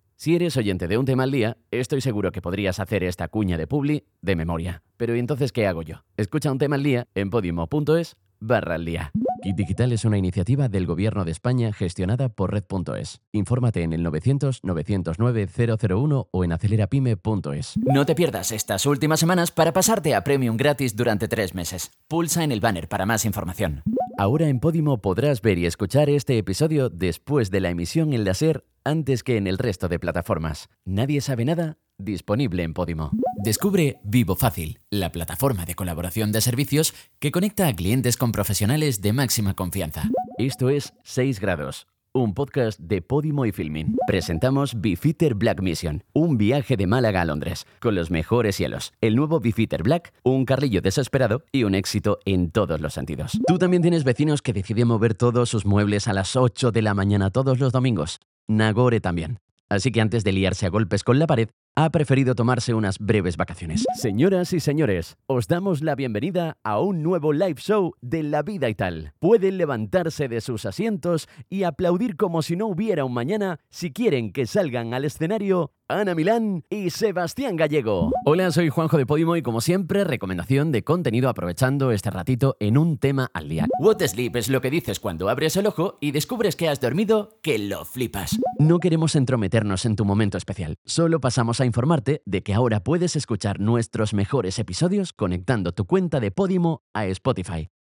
Muestras de voz (varios proyectos)